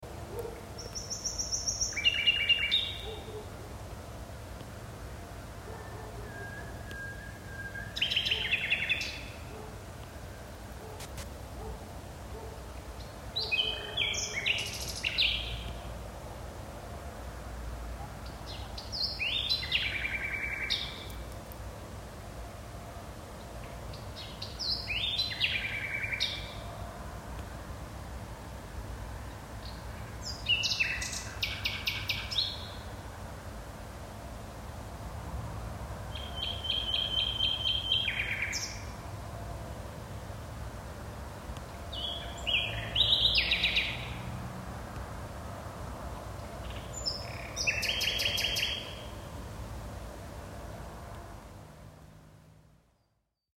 Nachtigallengesang im Garten
Sie geben alles, kombinieren um die 200 verschiedene Strophen und reagieren durchaus auch aufeinander.
Nachtigall.mp3